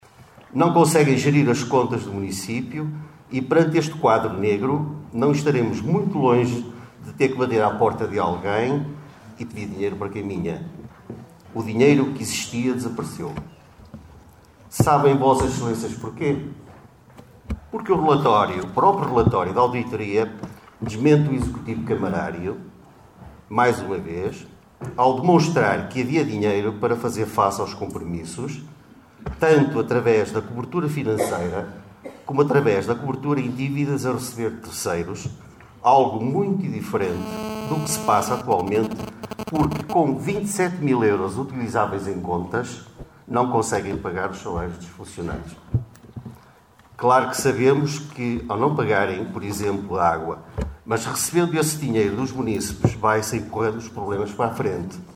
Excertos da última Assembleia Municipal.